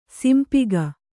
♪ simpiga